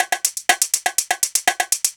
Perc Loop 122.wav